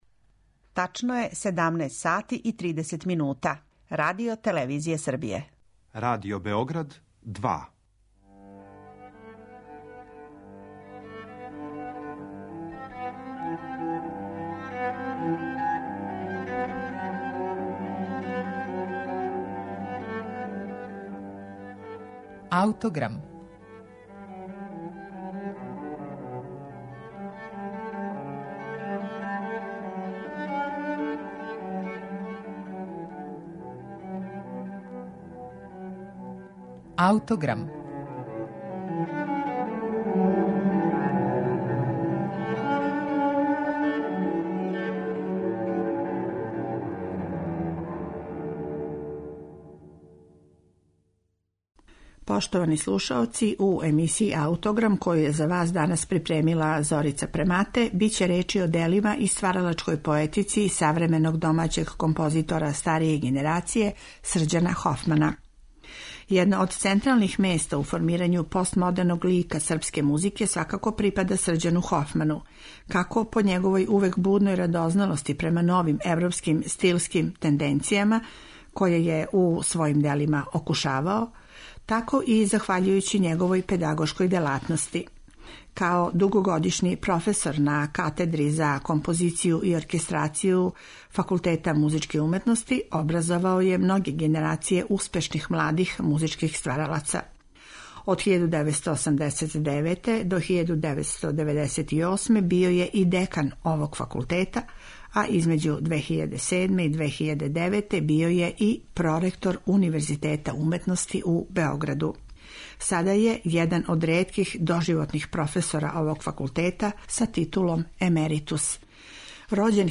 за клавир, гудаче и електронику
Емитоваћемо снимак начињен на јавном извођењу овог дела